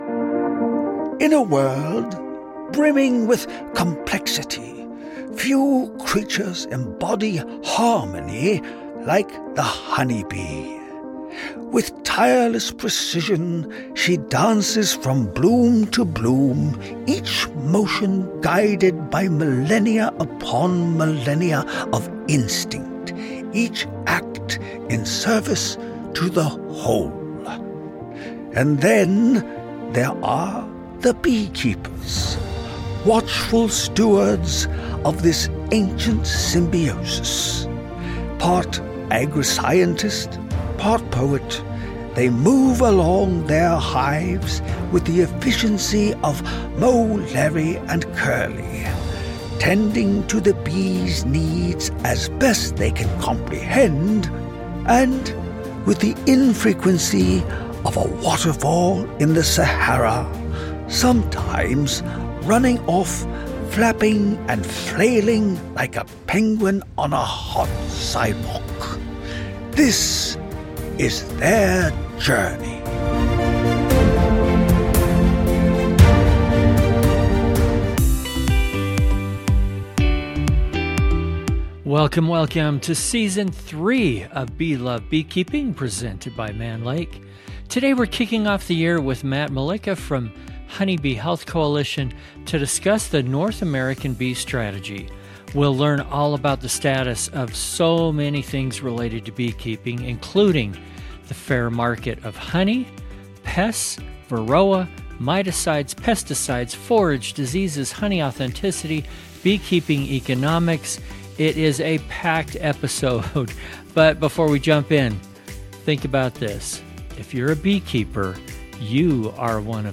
From Varroa mites and emerging threats like Tropilaelaps, to honey fraud, beekeeping economics, pesticides, forage, and applied research, this is a wide-ranging and practical conversation for beekeepers of all experience levels.